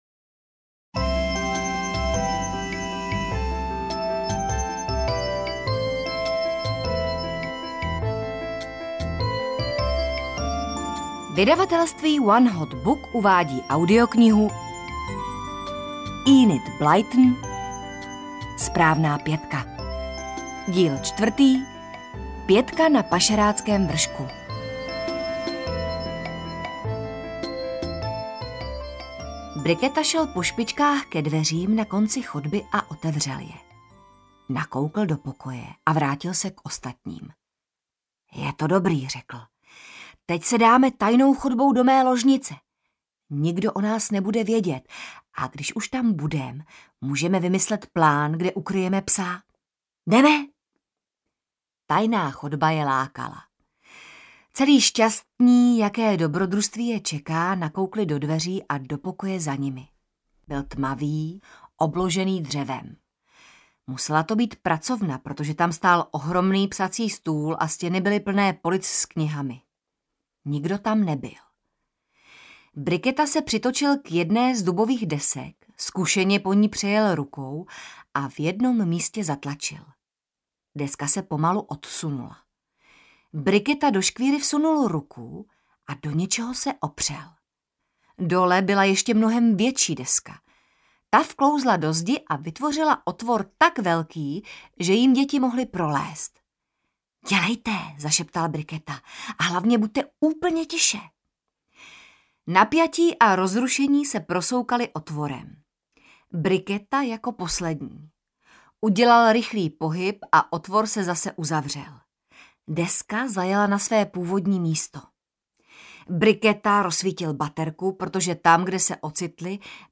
SPRÁVNÁ PĚTKA na Pašeráckém vršku audiokniha
Ukázka z knihy